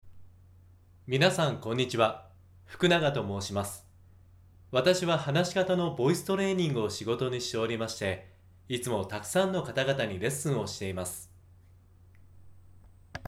「えー」「あのー」がない自己紹介
えーあー症候群の話し方に比べると、印象が良く聞こえませんか？